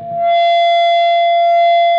PRS FBACK 6.wav